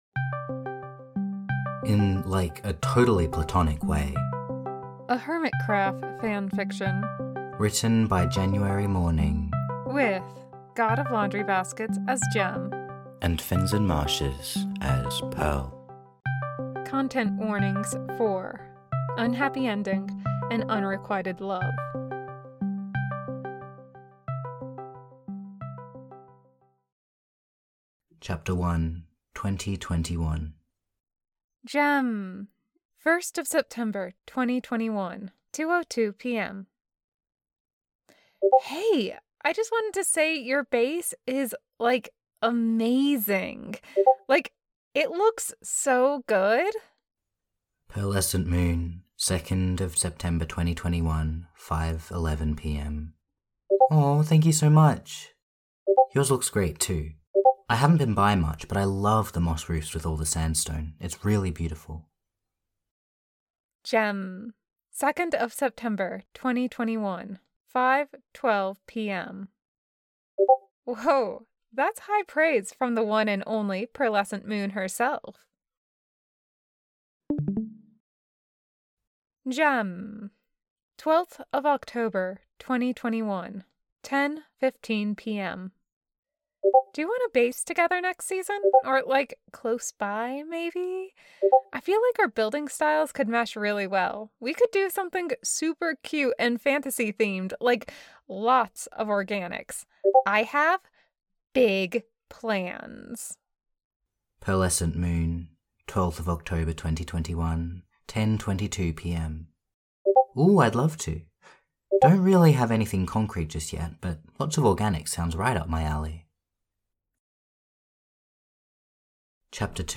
fandom: video blogging rpf relationship: geminitay/pearl | pearlescentmoon info: collaboration|two voices